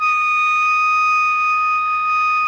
SOPRANO D#5.wav